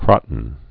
(krŏtn)